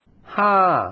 Ha